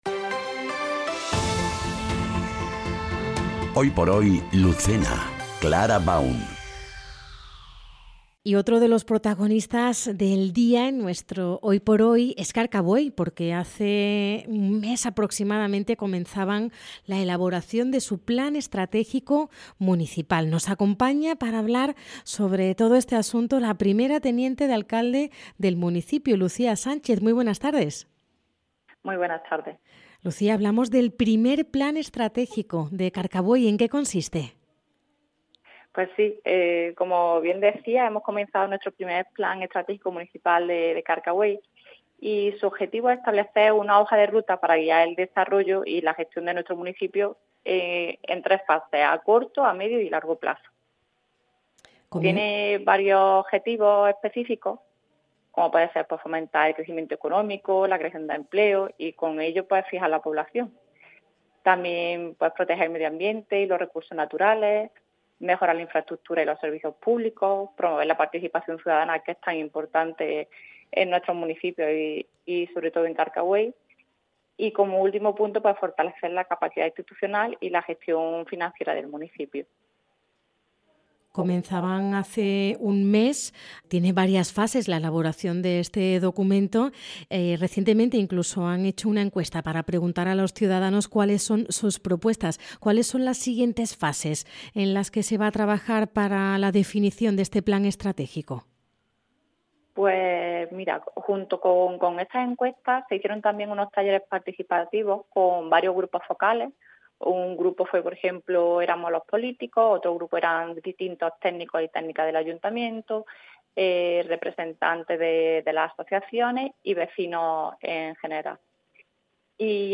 ENTREVISTA | Plan Estratégico de Carcabuey
En Hoy por Hoy Andalucía Centro Lucena entrevistamos a Lucía Sánchez, primera teniente de Alcaldía en el Ayuntamiento de Carcabuey, sobre la elaboración del primer Plan Estratégico del municipio que se está desarrollando.